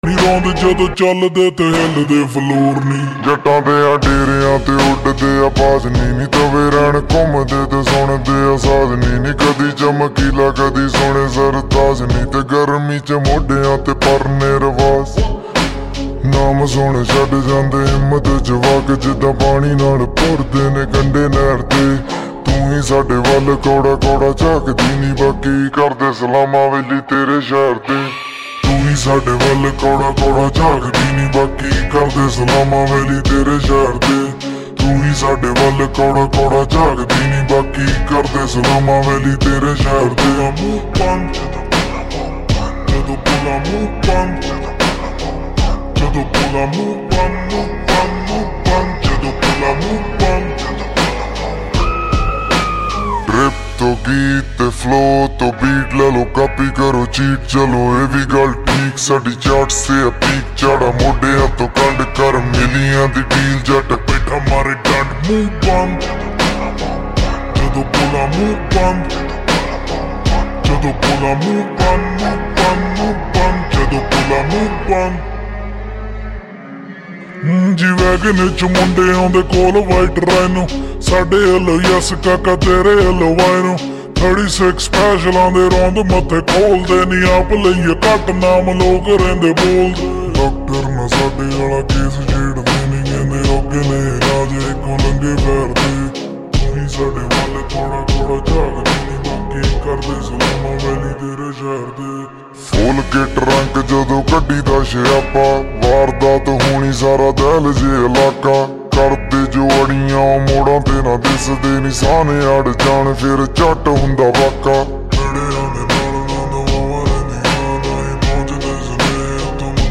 SLOWED REVERB SONG